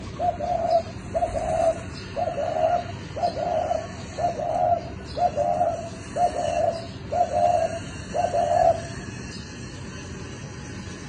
珠颈斑鸠鸣叫声